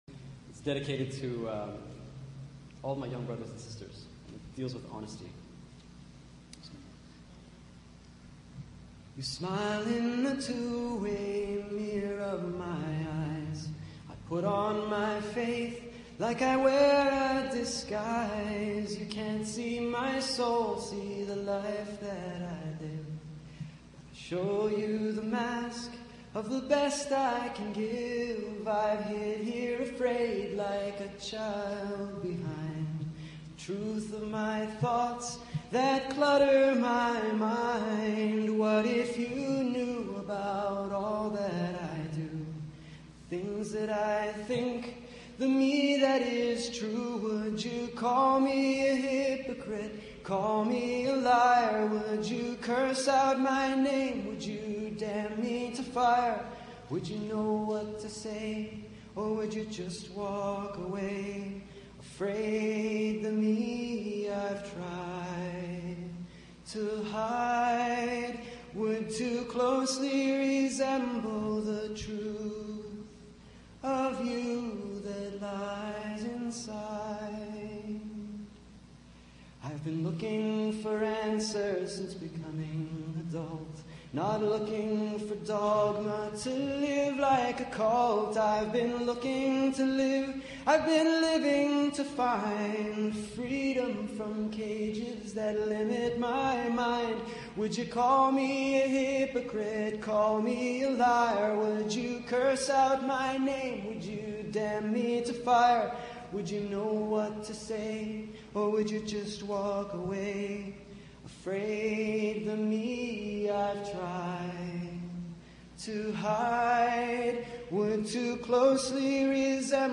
Nasheed.